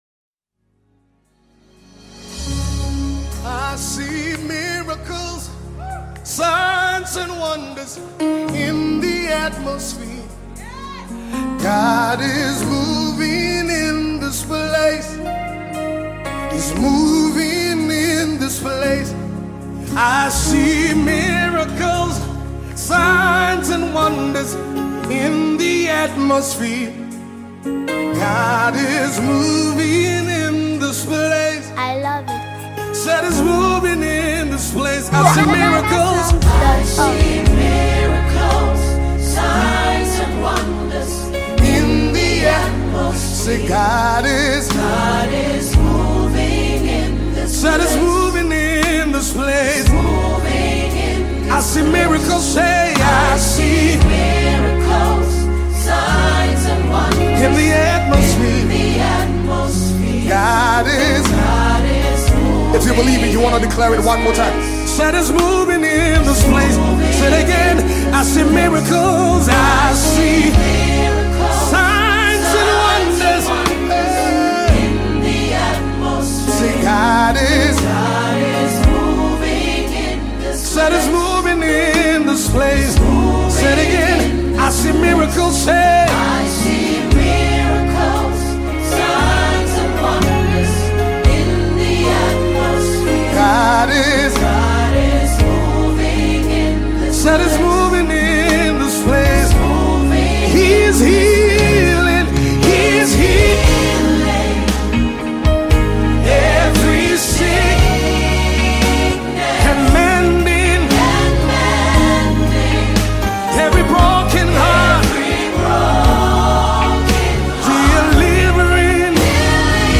Ghanaian Gospel